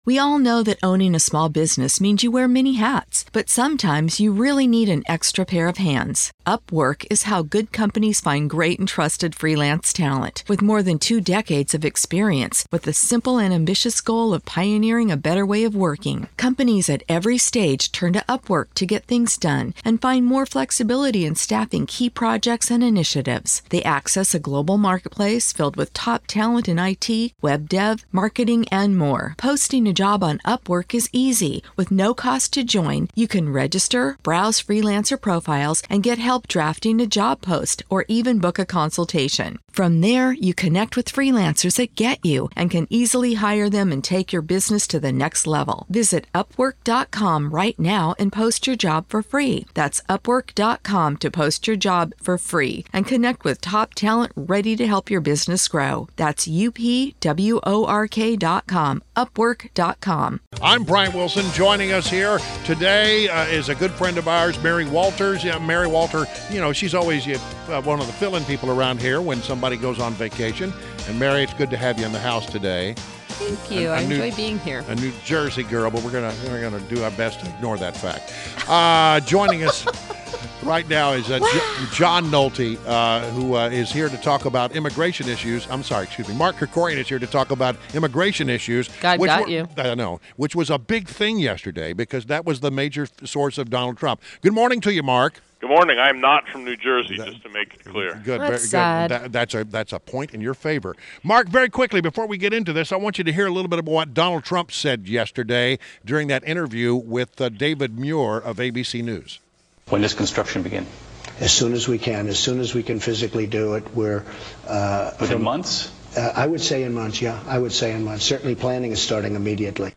INTERVIEW — MARK KRIKORIAN — a nationally recognized expert on immigration issues and is the Executive Director of the Center for Immigration Studies (CIS)